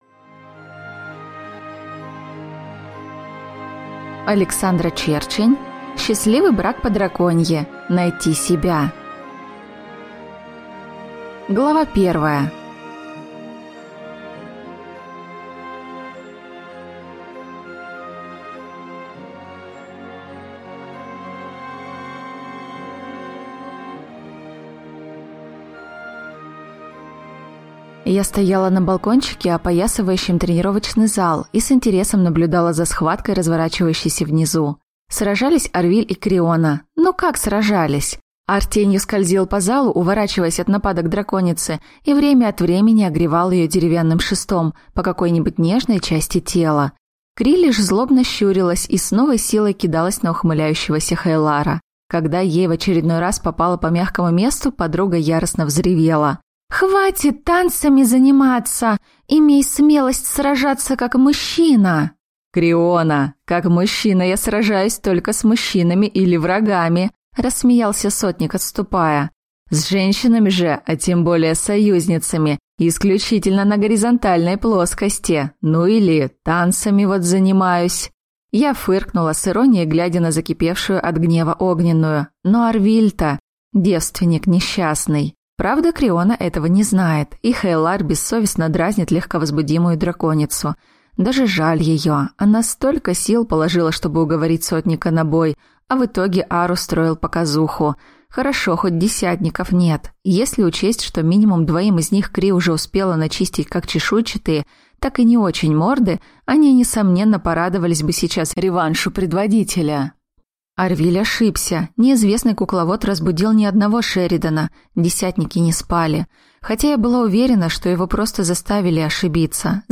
Аудиокнига Счастливый брак по-драконьи. Найти себя - купить, скачать и слушать онлайн | КнигоПоиск